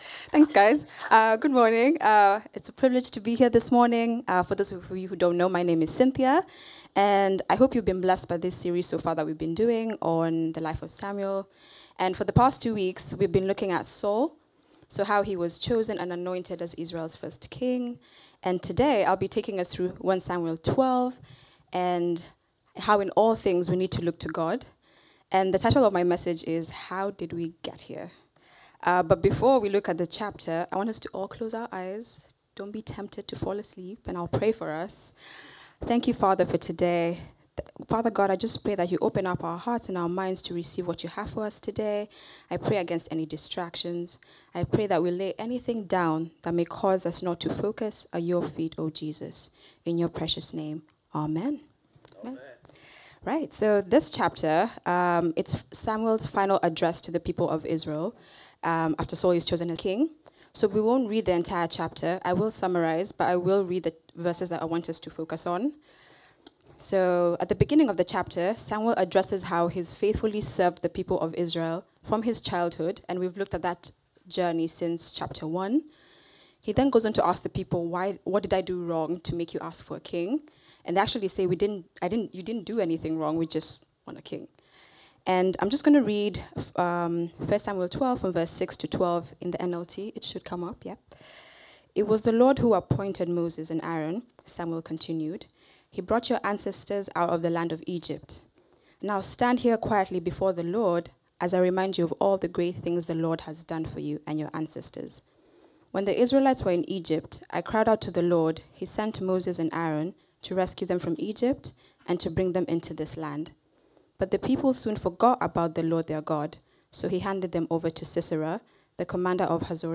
Download A Warning | Sermons at Trinity Church